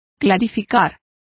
Complete with pronunciation of the translation of clarify.